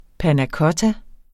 Udtale [ panaˈkʌta ]